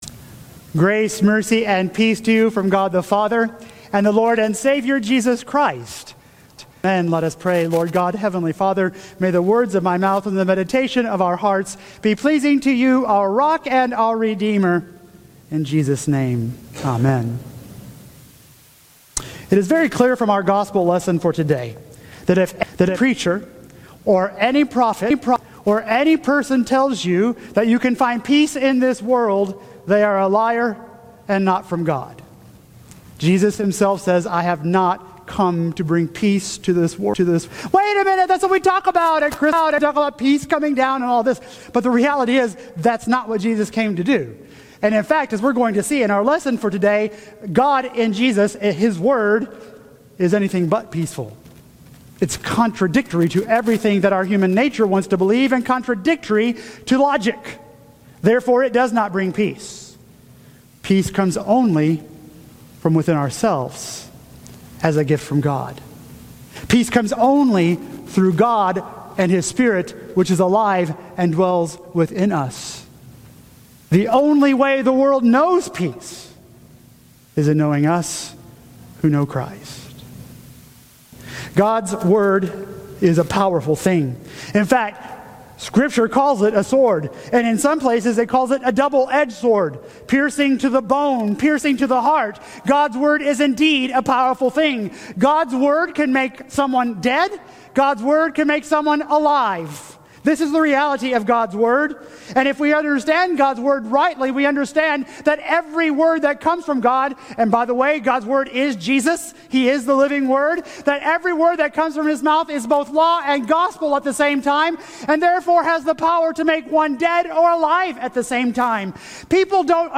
Here is our full worship service